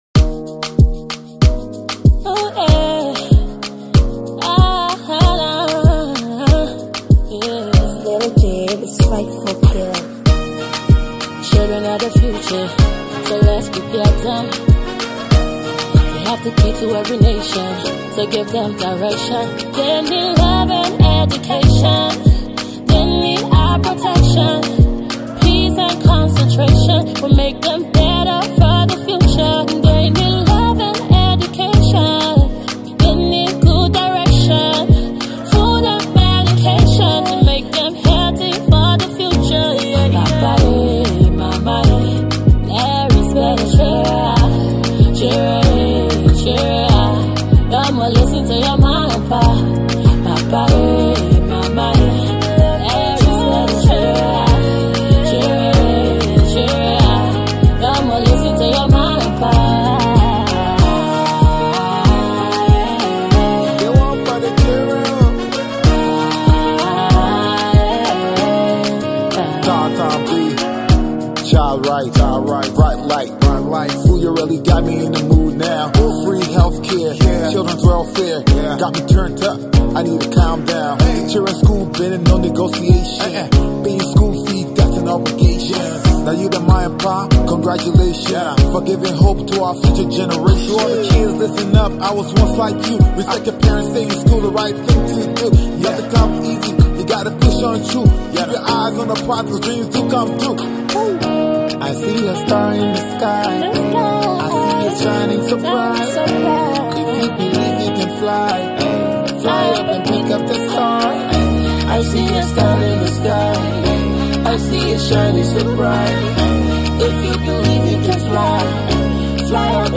/ Afro-Pop, Colloquial / By
a beautiful and meaningful tune for all children.